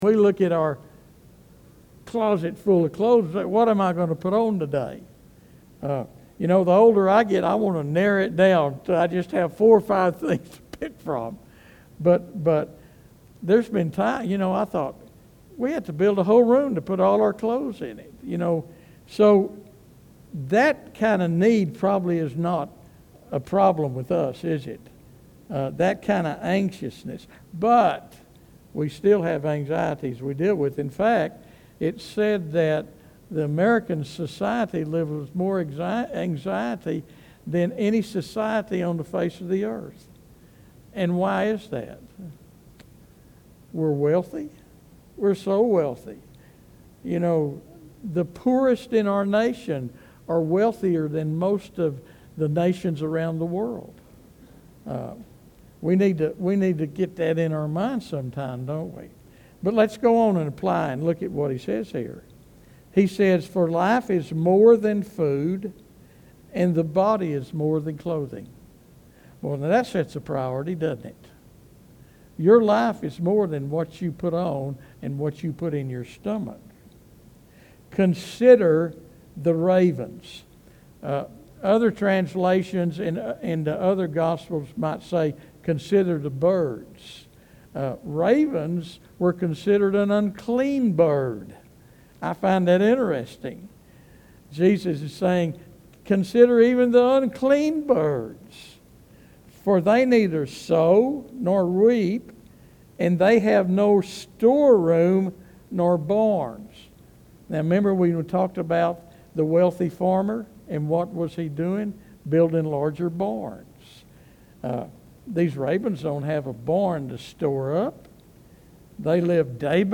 Proper Time Investment - Focusing on eternal rather than temporal things This sermon challenges us to examine where our hearts truly are by looking at where we place our treasure. Are we building up riches that moths can destroy and thieves can steal, or are we investing in the kingdom of heaven?